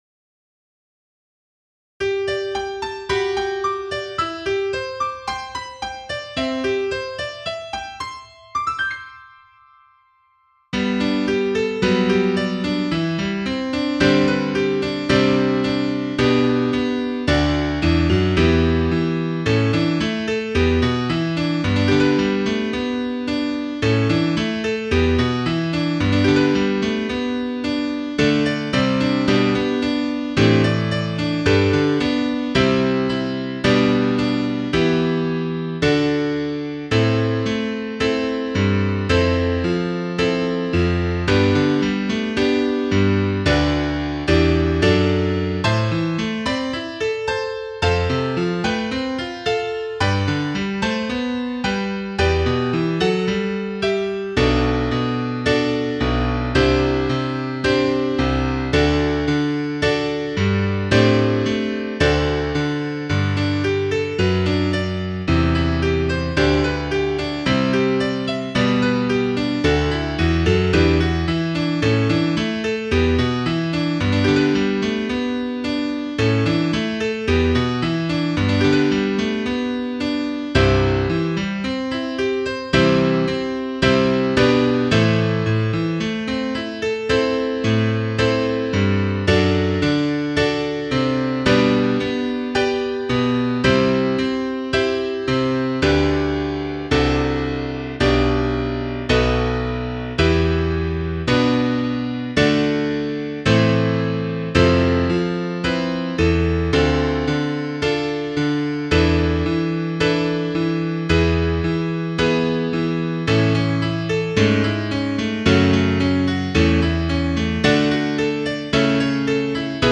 Piano
4_Ecce_enim_Piano.mp3